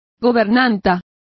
Complete with pronunciation of the translation of governesses.